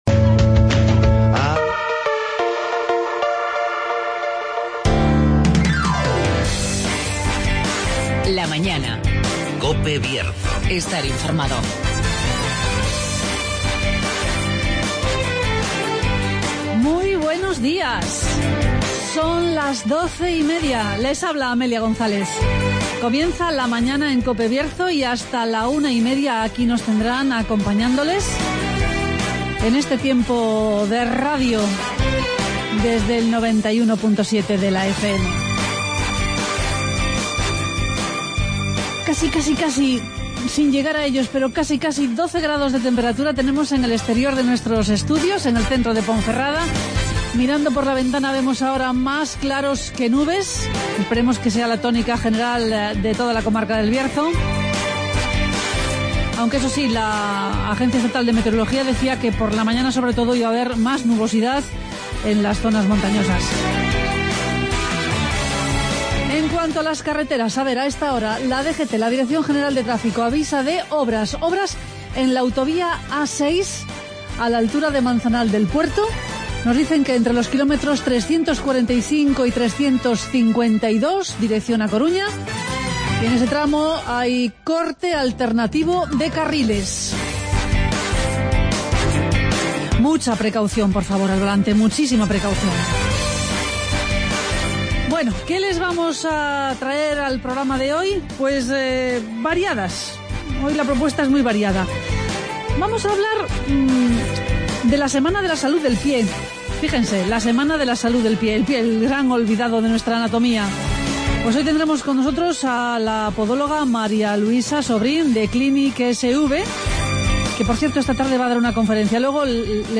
Hoy entrevistamos al portavoz del PP en el Ayuntamiento de Cacabelos, Adolfo Canedo;